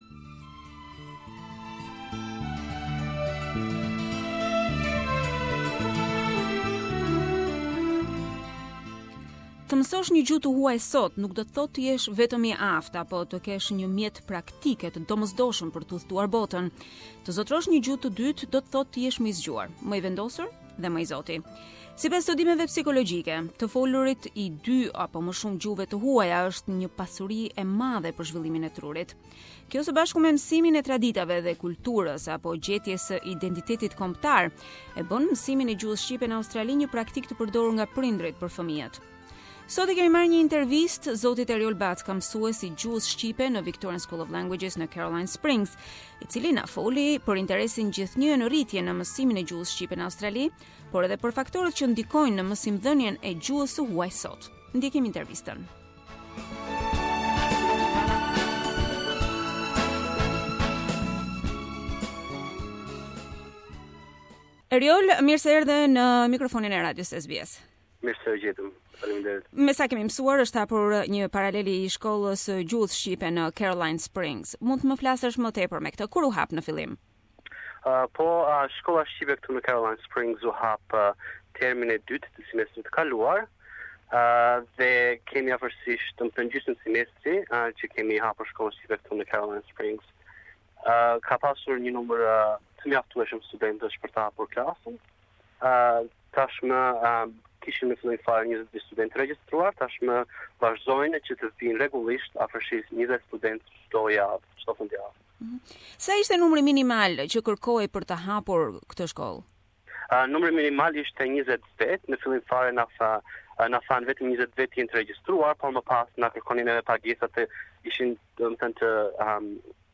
Interview - Albanian Language Classes